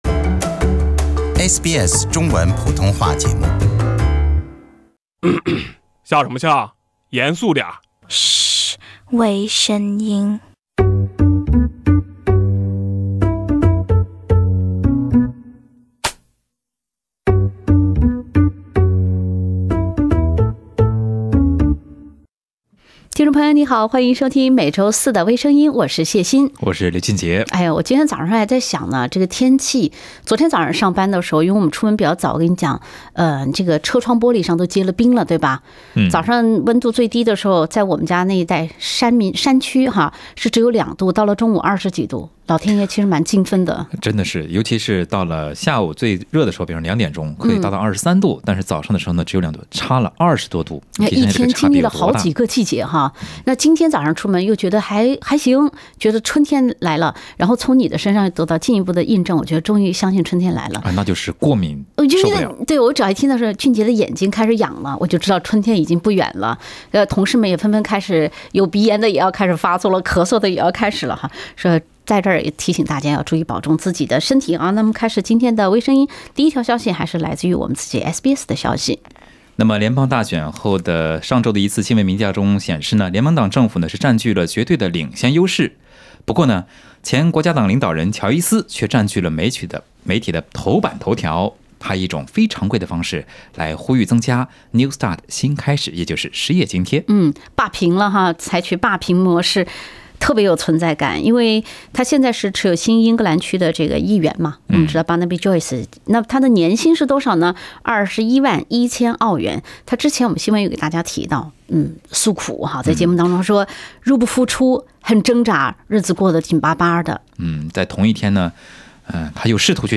另类轻松的播报方式，深入浅出的辛辣点评，包罗万象的最新资讯，倾听全球微声音。